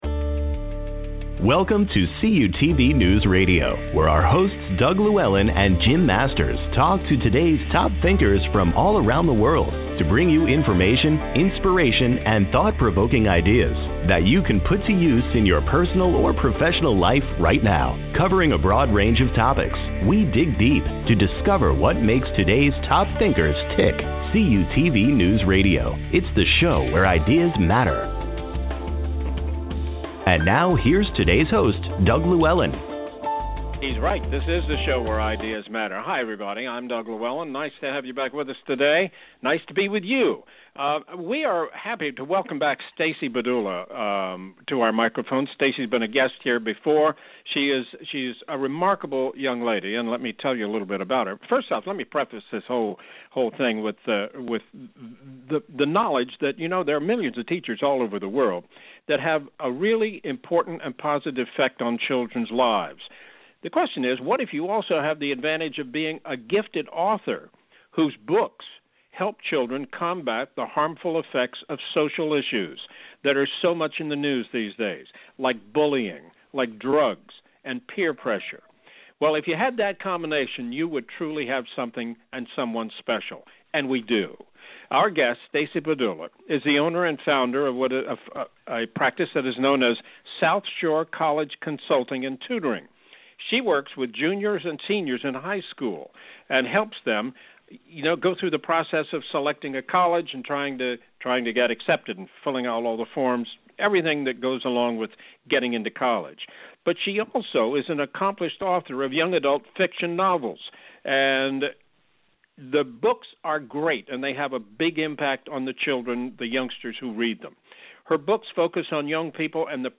During a recent radio interview, I was asked to discuss my faith, as it is a recurring theme woven throughout my novels.